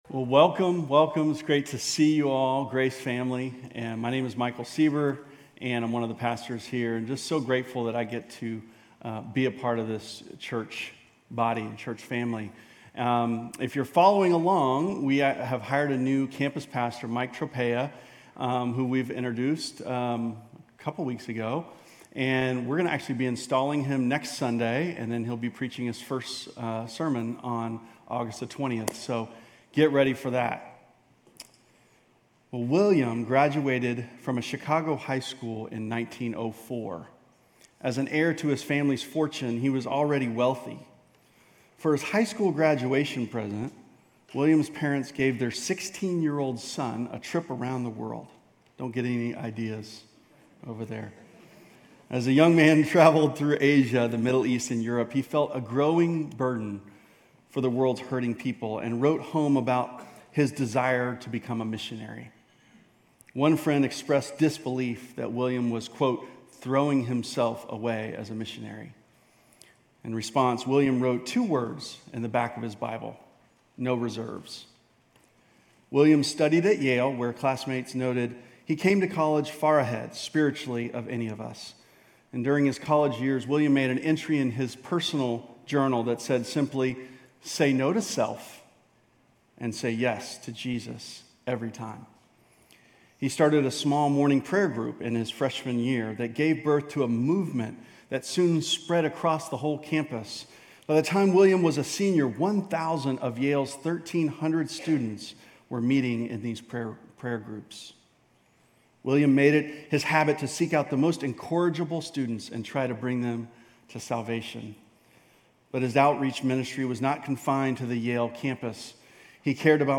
GCC-UB-July-30-Sermon.mp3